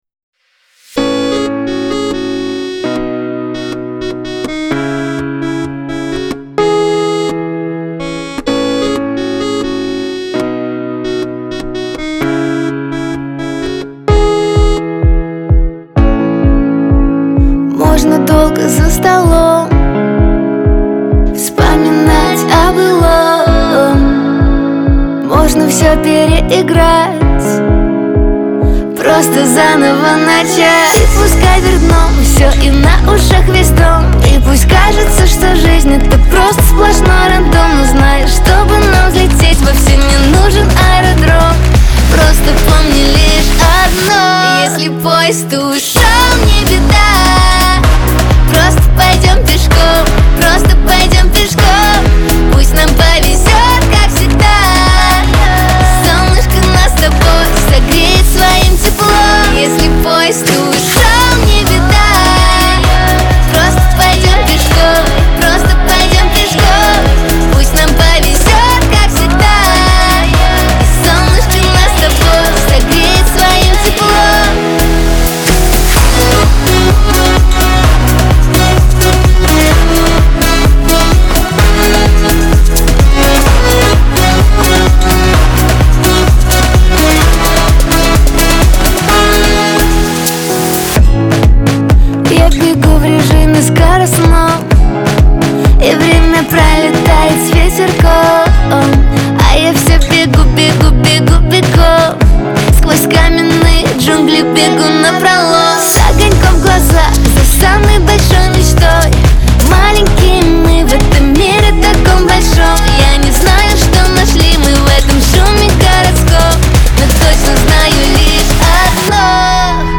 весёлая музыка